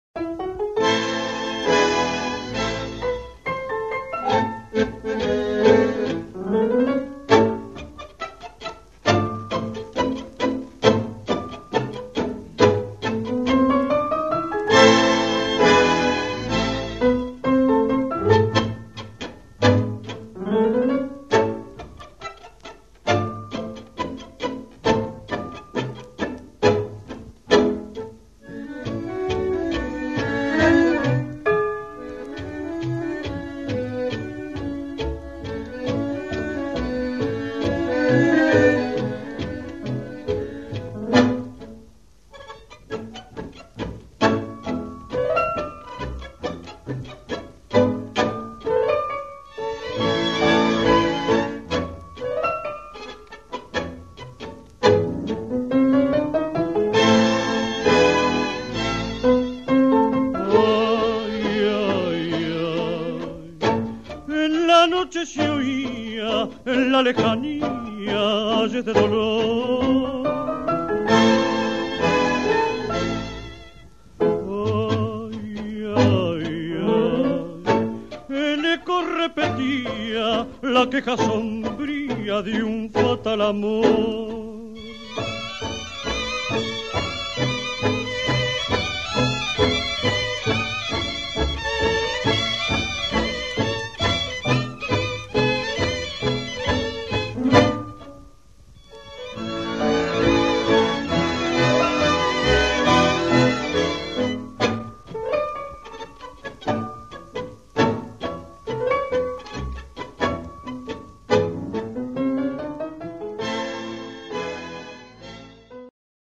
Pozdravljeni ljubitelji dobre tango glasbe!